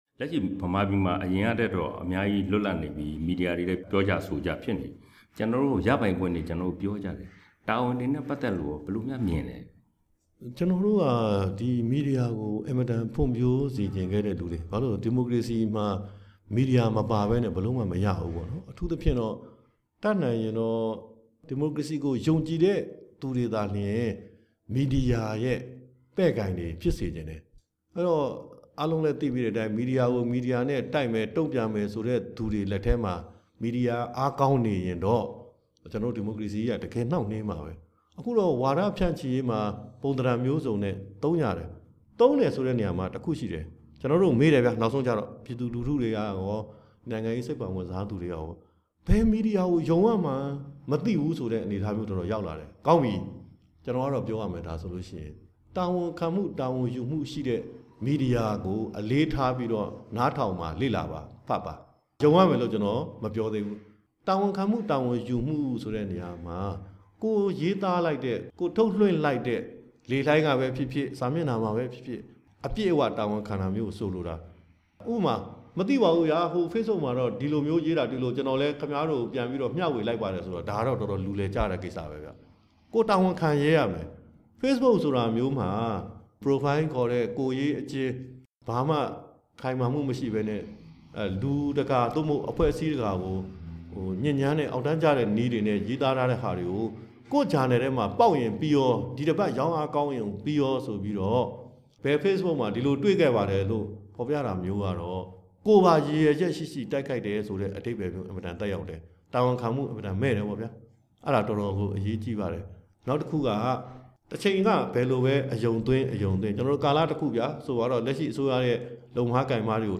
သတိထားရမယ့် သတင်းမီဒီယာတွေအကြောင်း ကိုမင်းကိုနိုင်နဲ့ မေးမြန်းချက်